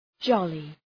Προφορά
{‘dʒɒlı}